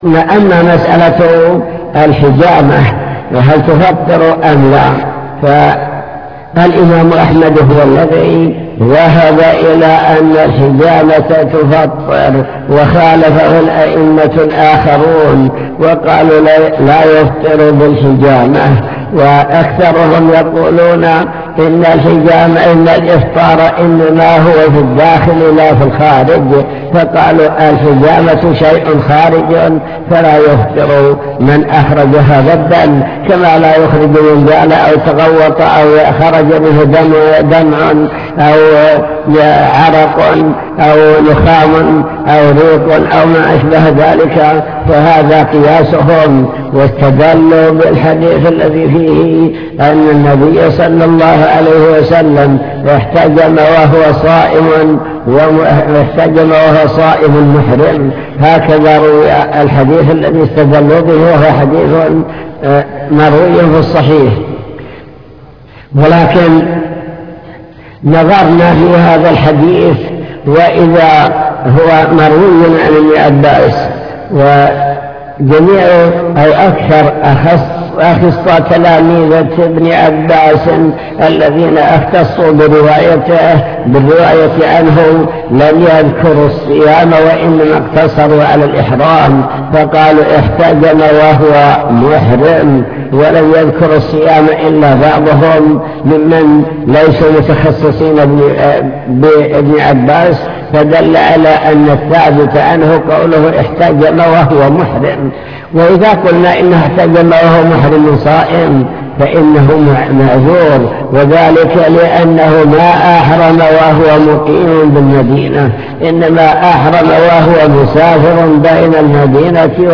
المكتبة الصوتية  تسجيلات - محاضرات ودروس  الافتراق والاختلاف الكلام عن الخلاف وأسبابه